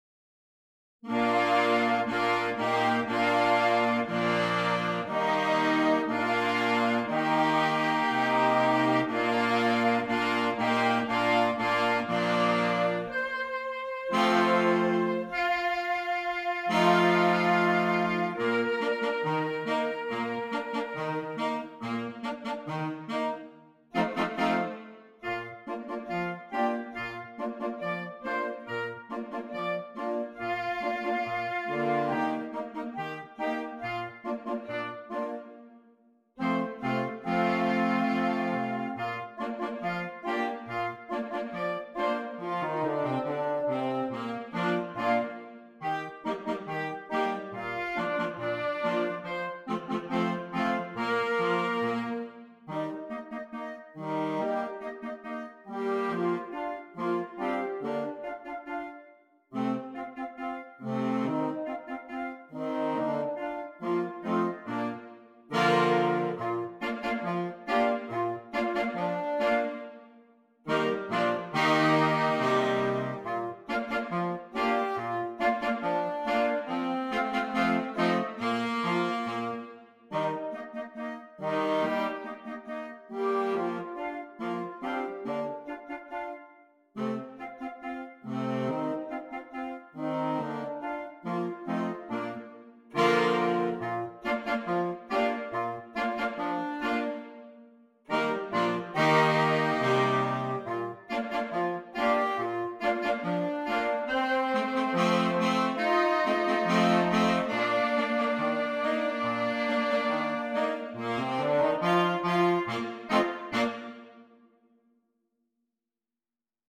Christmas
Interchangeable Woodwind Ensemble